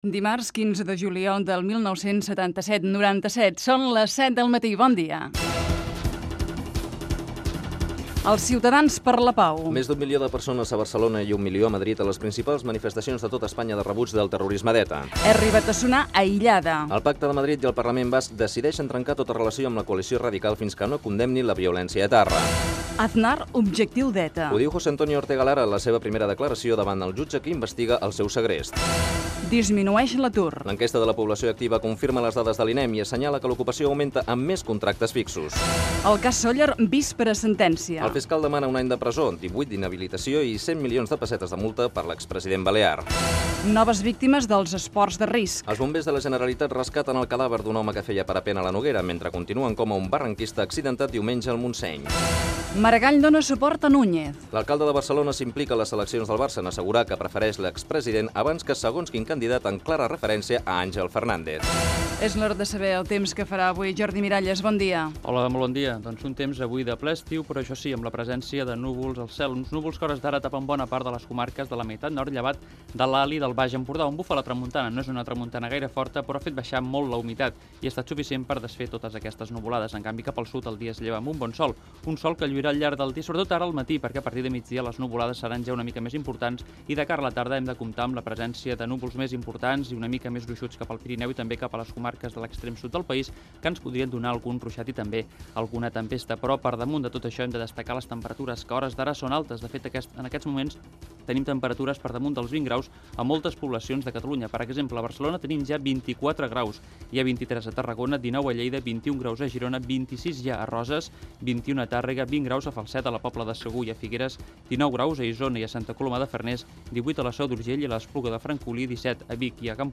Data i hora, titulars informatius de les 07.00 del matí. El temps, el trànsit, hora i indicatiu del programa. ETA ha assassinat el polític del Partido Popular Miguel Ángel Blanco, informació de la manifestació de rebuig a Barcelona (declaració de l'alcalde de Barcelona Pasqual Maragall)
Info-entreteniment